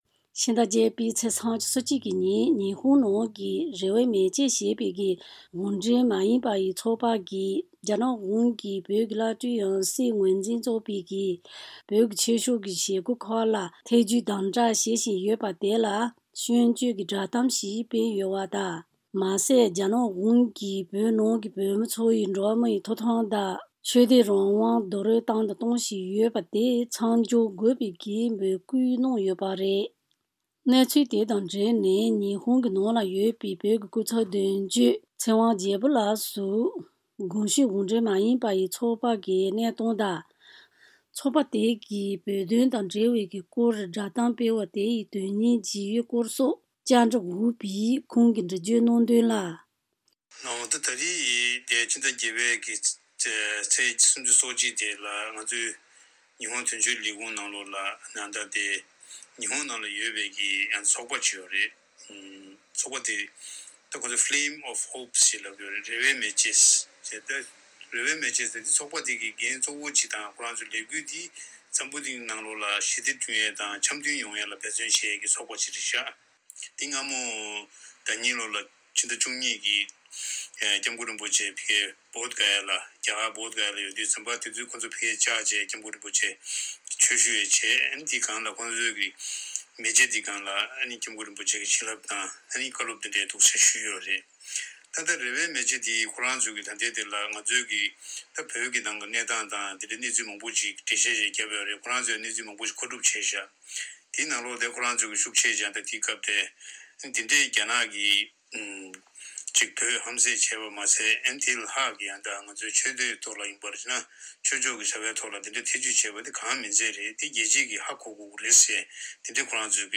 གསར་འགྱུར་དཔྱད་བརྗོད་ནང་།